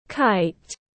Cái diều tiếng anh gọi là kite, phiên âm tiếng anh đọc là /kaɪt/
Kite /kaɪt/